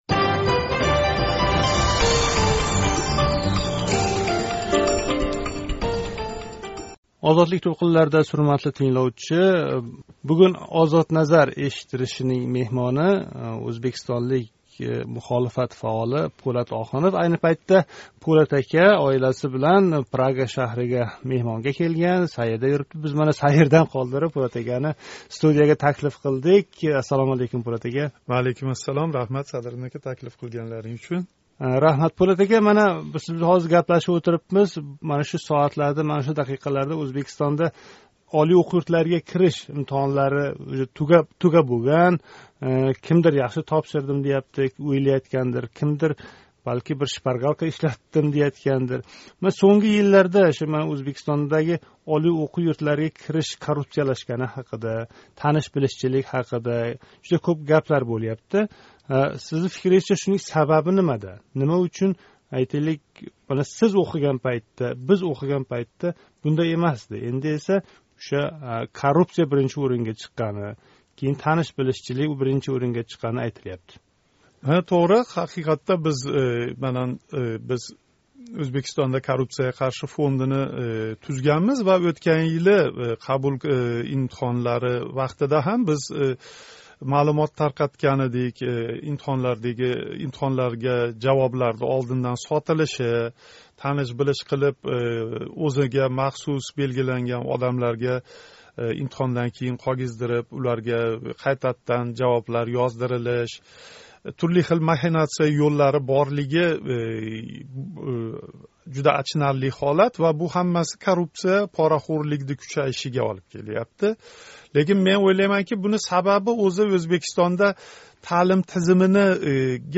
Мухолифат фаоли¸ собиқ СССР халқ депутати Пўлат Охунов Озодлик студиясида
Пўлат Охунов билан суҳбат